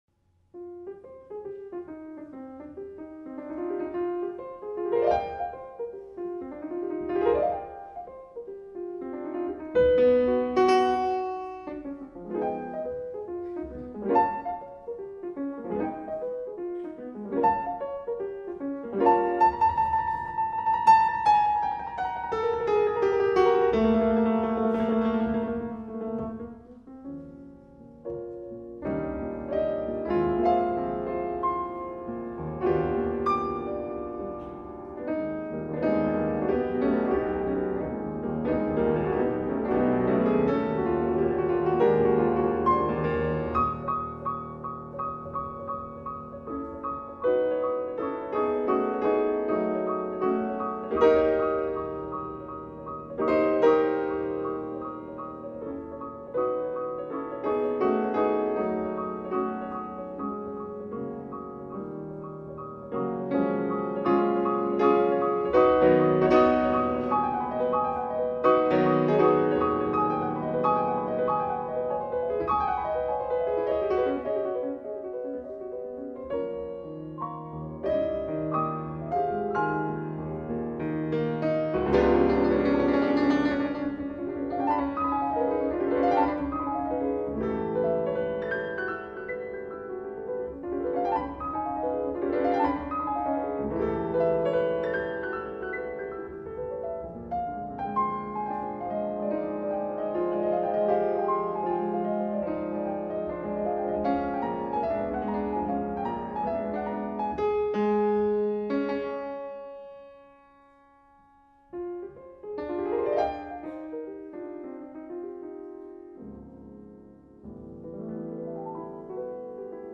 een prelude.